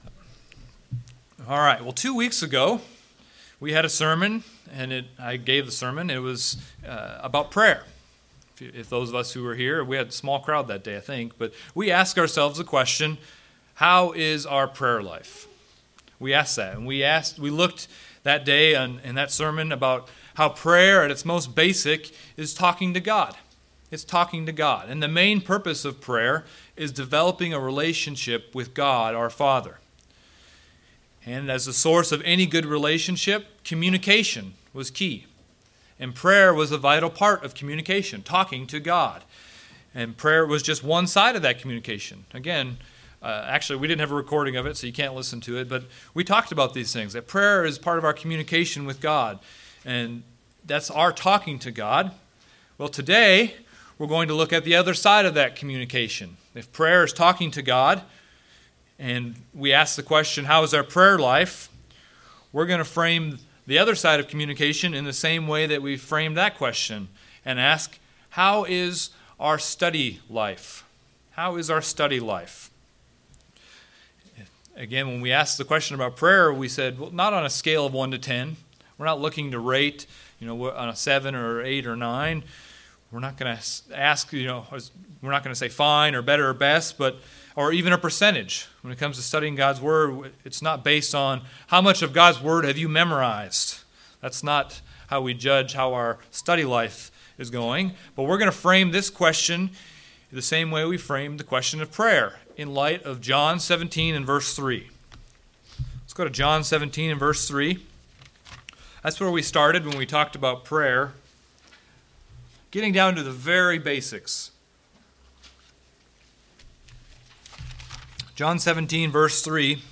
Whether we are struggling or just looking to improve our study life, this sermon explores different ways to do just that.
Given in Springfield, MO